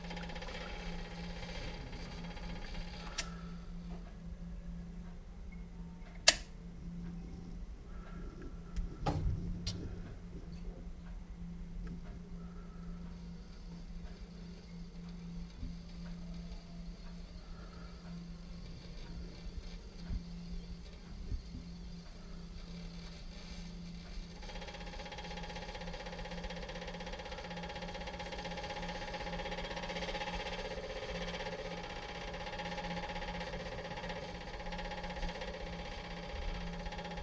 Mystery sound from immersion heater